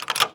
door_lock_open_02.wav